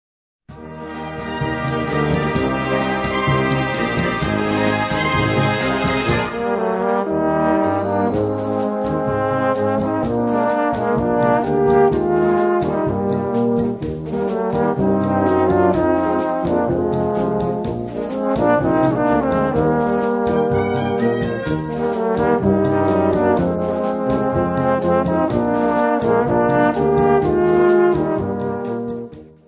Gattung: Solo für zwei Tenöre und Blasorchester
Besetzung: Blasorchester